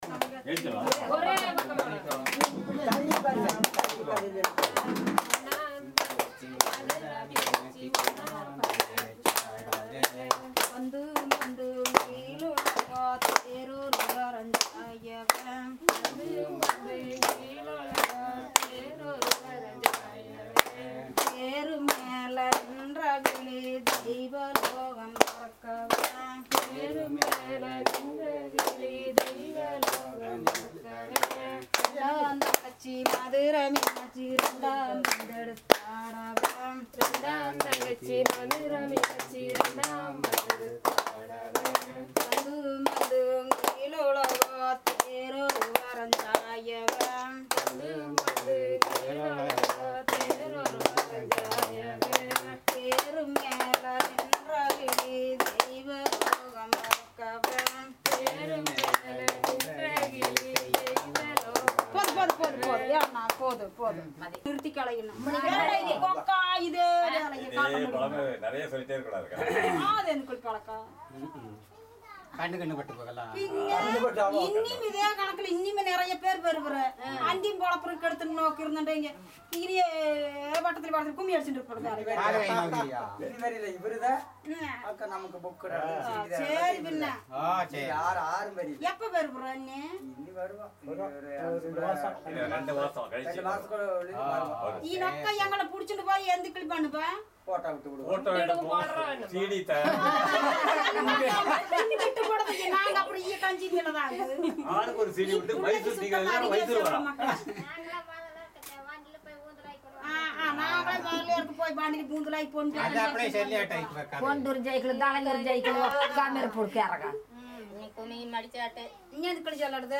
Performance of a group song